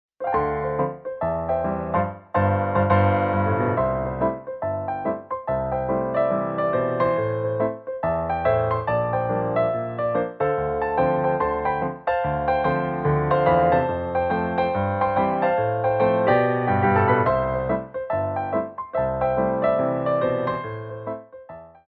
Piano Arrangements of Pop & Rock for Tap Class
MEDIUM TEMPO